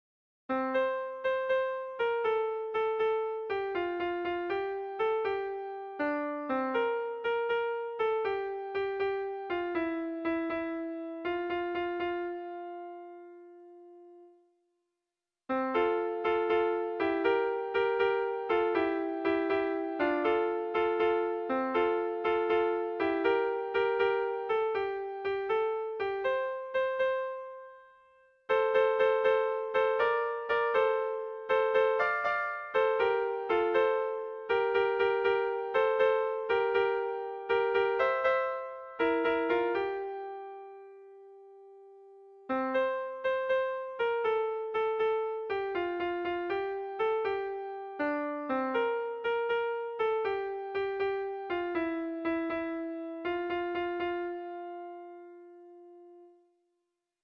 Kontakizunezkoa